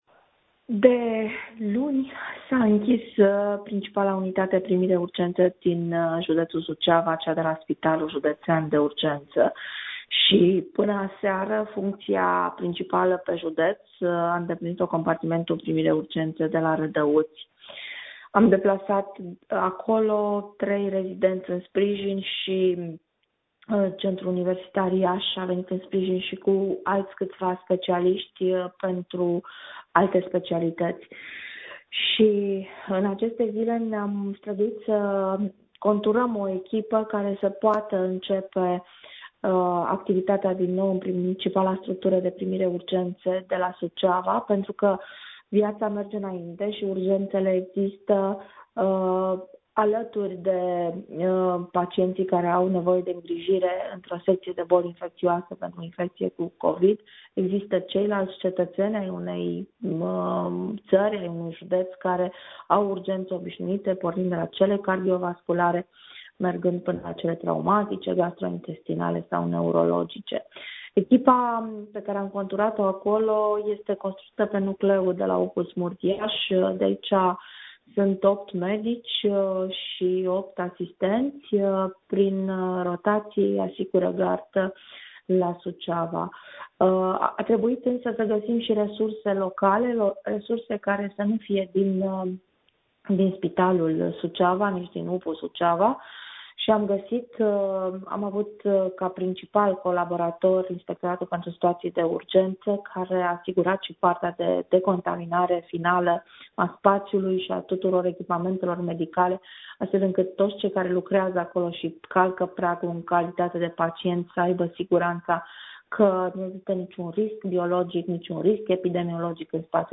Într-o declaraţie acordată în exclusivitate colegului nostru